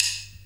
D2 SDRIM05-R.wav